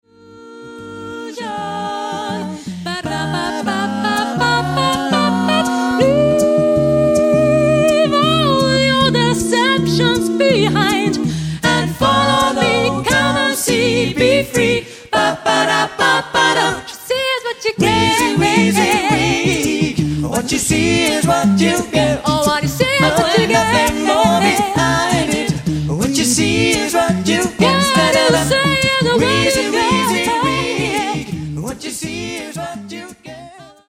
As mp3 stereo files